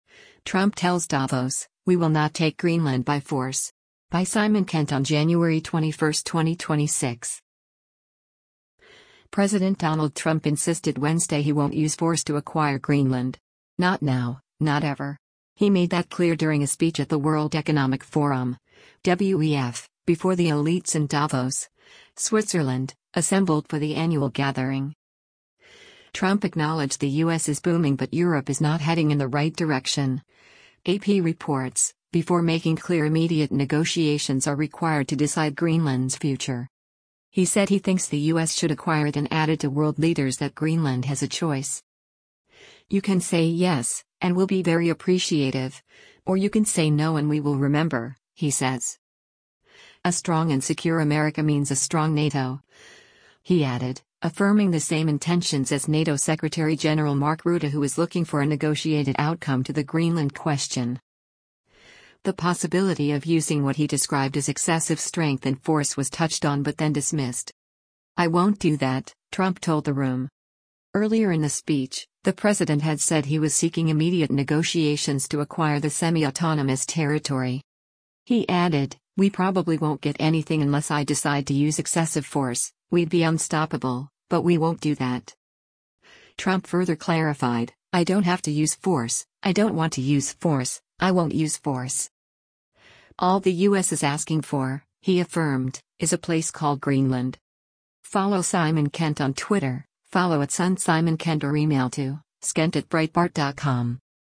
He made that clear during a speech at the World Economic Forum (WEF) before the elites in Davos, Switzerland, assembled for the annual gathering.